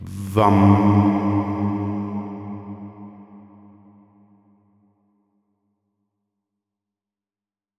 BIJA MANTRA VA
MANTRA